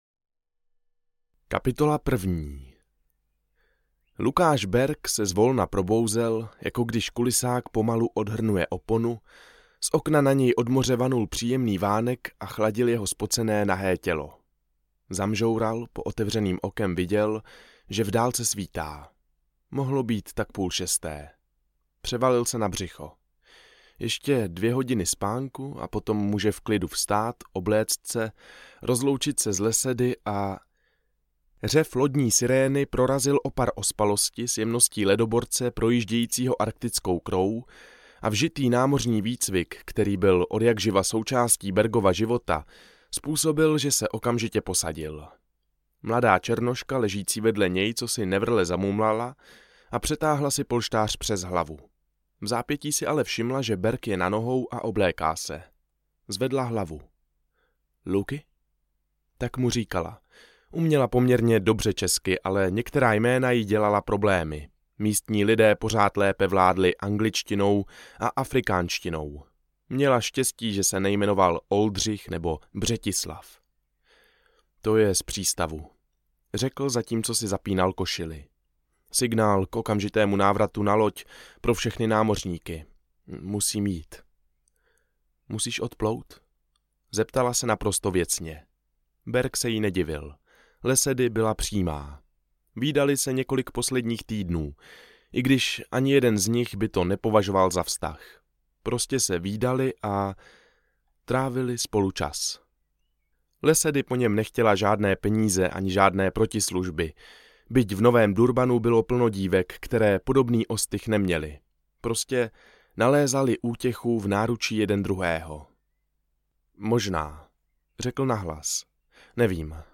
Kategorie: Dobrodružné